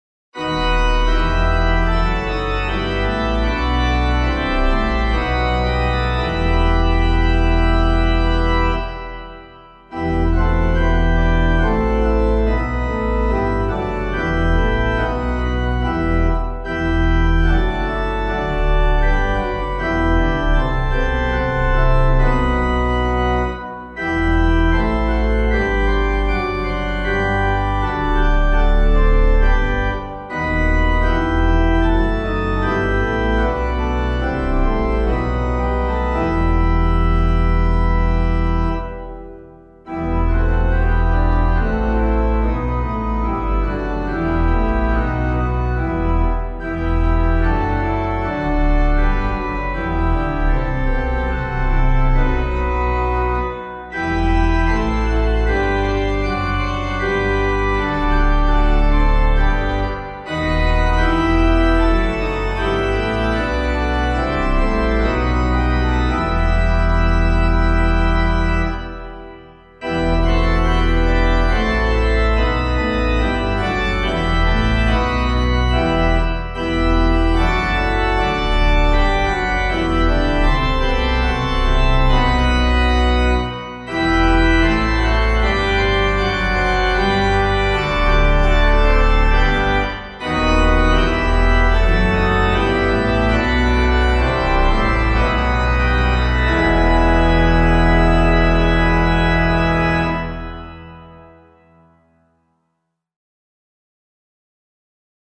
Hymn suitable for Catholic liturgy